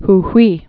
(h-hwē)